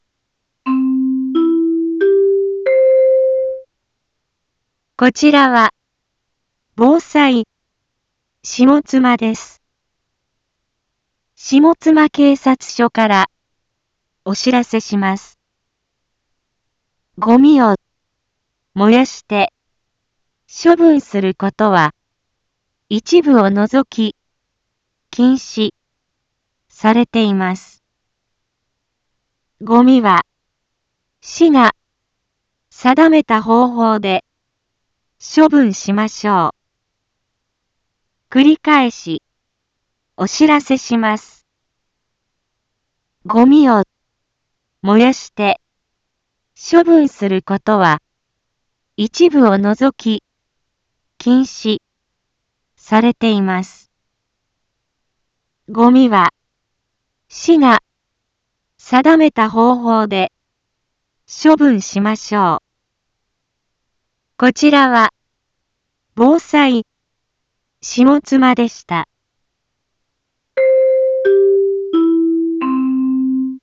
一般放送情報
Back Home 一般放送情報 音声放送 再生 一般放送情報 登録日時：2026-02-25 10:01:35 タイトル：ごみの野焼き禁止（啓発放送） インフォメーション：こちらは、ぼうさいしもつまです。